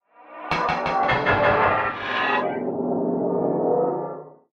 Загадочный звук портала